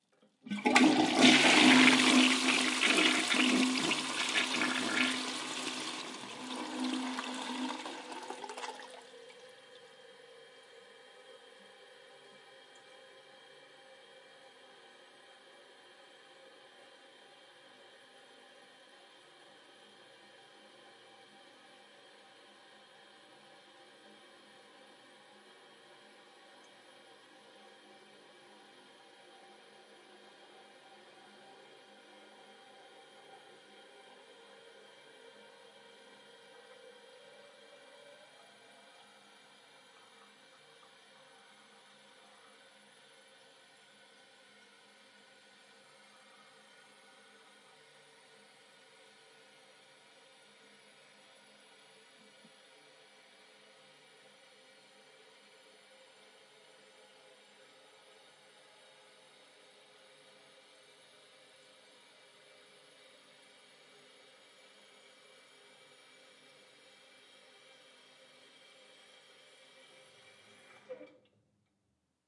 水声 " 冲洗 02
描述：使用Zoom H1和Rode Videomic录制
Tag: 管道 冲洗 FL ushing 卫生间 浴室 厕所